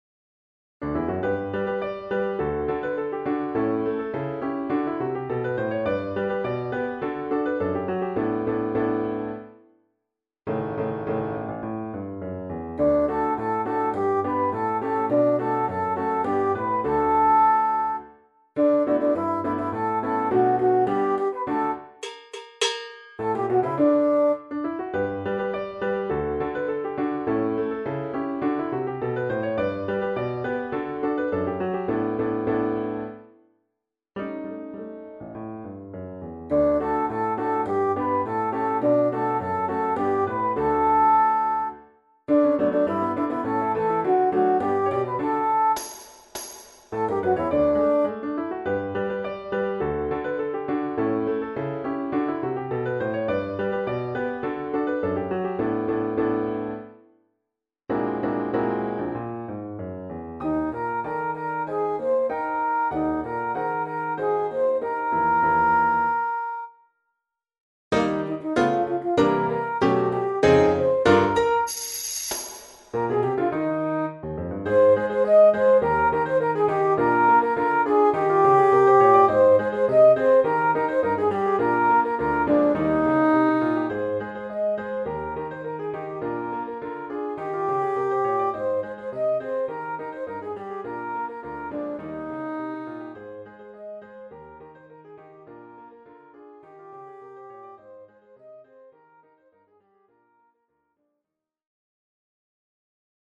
Chorale d'Enfants (3 à 6 ans) et Piano